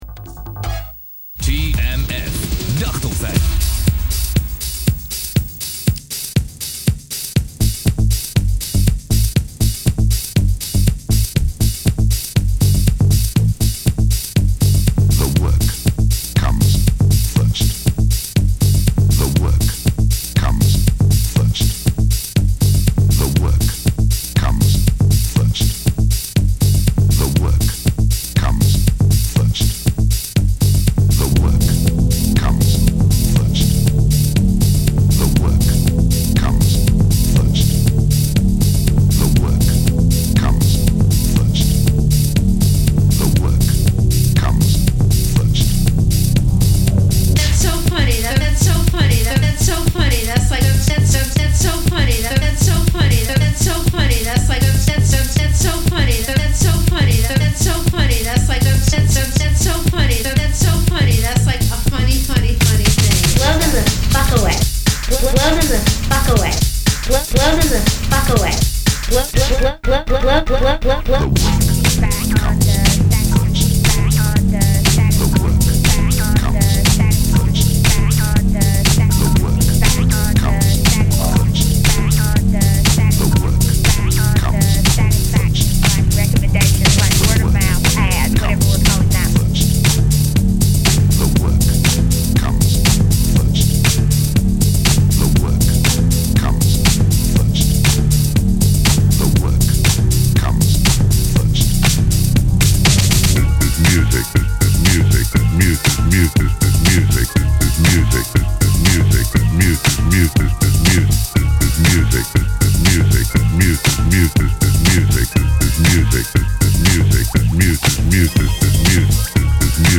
Way back in the last century I made a bunch of songs about the place I worked, built out of sounds submitted by the people who worked there.